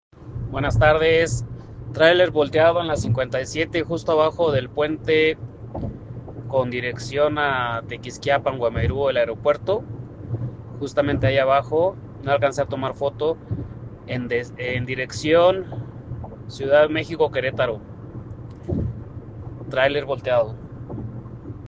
Reporte: Vuelca tráiler en la 57 en entronque con 57D